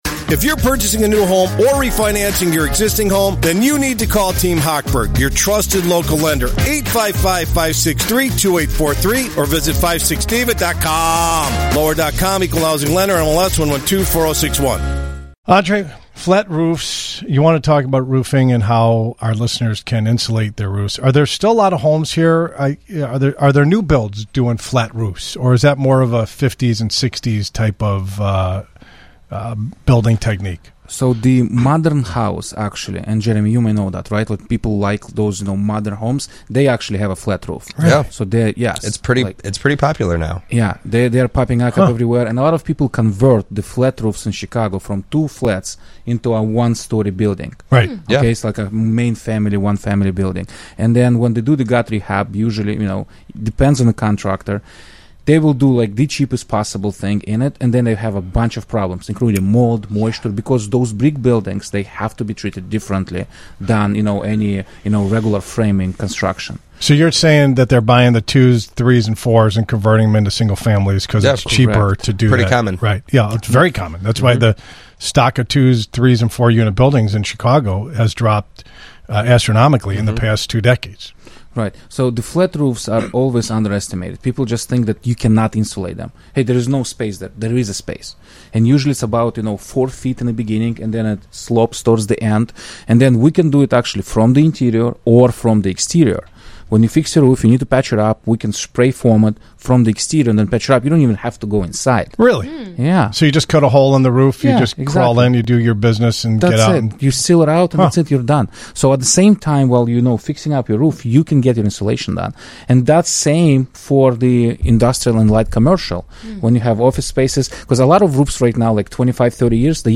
Featured on WGN Radio’s Home Sweet Home Chicago on 09/27/25